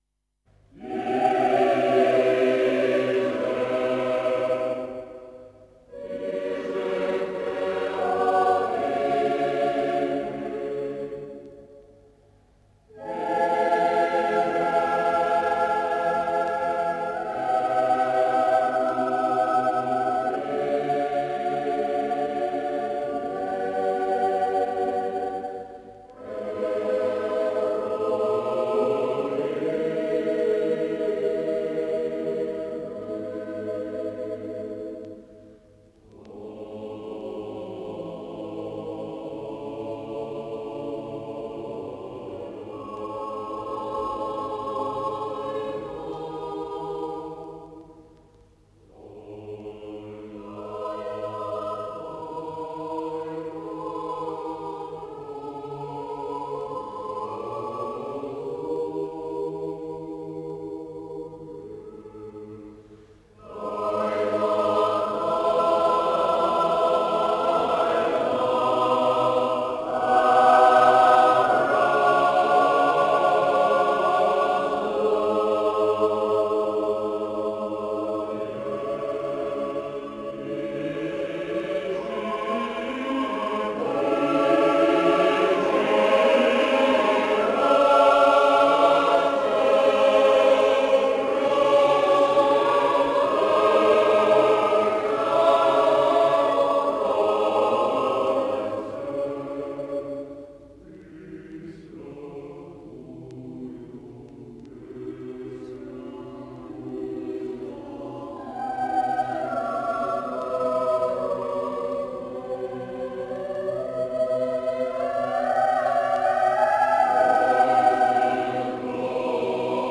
Восьмиголосний партесний розспів «Херувимська» засвідчив новаторство українського композитора й мислителя-енциклопедиста Г. Сковороди.
Виконує Хор студентів Київської державної консерваторії імені П. І. Чайковського
Художній керівник і дириґент Павло Муравський
skovoroda-heruvimska-horove-vikonannja.mp3